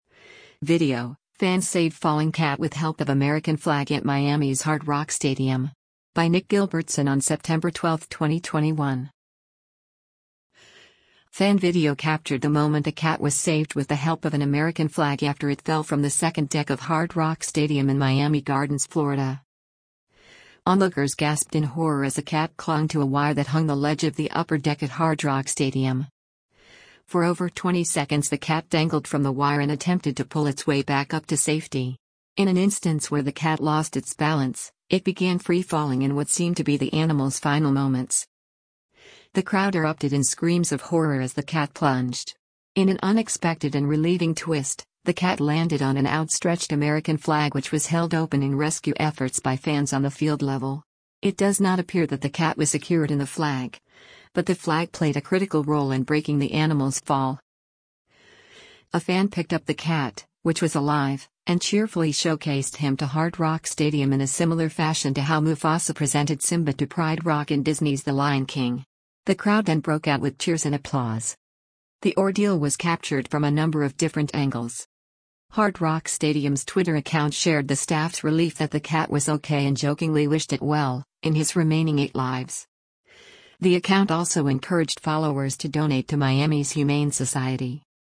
Fan video captured the moment a cat was saved with the help of an American flag after it fell from the second deck of Hard Rock Stadium in Miami Gardens, Florida.
The crowd erupted in screams of horror as the cat plunged.
The crowd then broke out with cheers and applause.